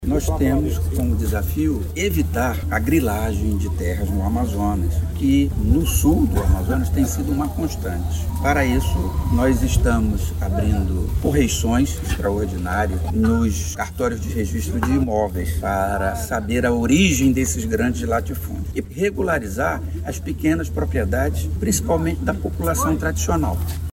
O desembargador Jomar Fernandes, do TJAM explica que a investigação também tem como foco o combate à grilagem de terras.